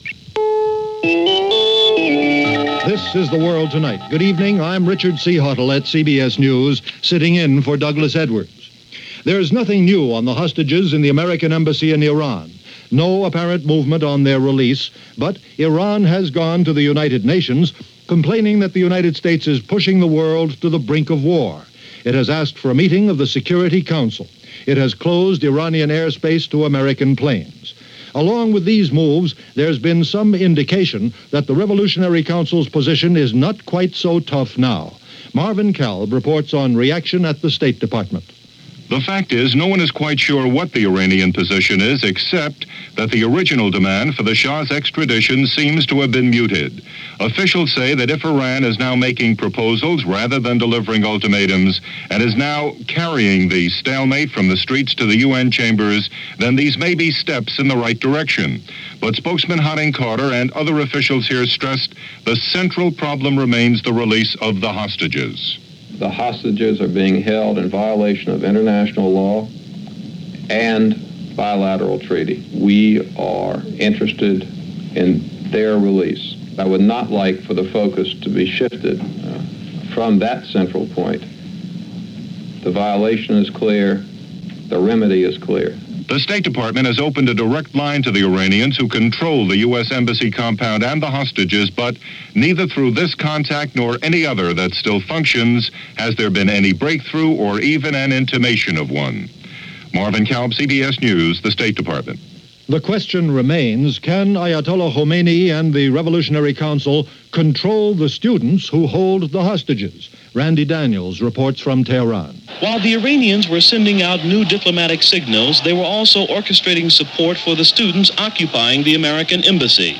November 13, 1979 – CBS Radio – The World Tonight – Gordon Skene Sound Collection –
Familiar words, familiar stories – and that’s just a small slice of what went on, this November 13, 1979 as presented by CBS Radio’s The World Tonight.